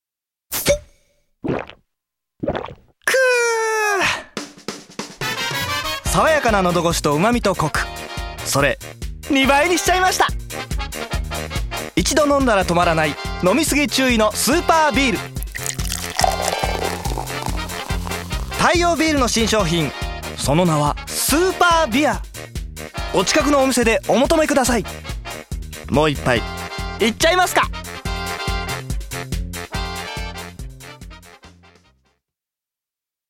ボイスサンプル
CM１